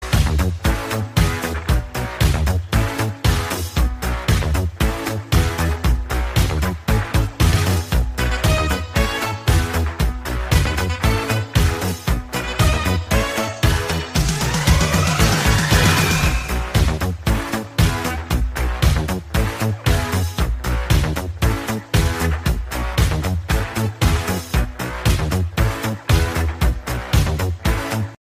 It is a Little bit beat remix ringtone.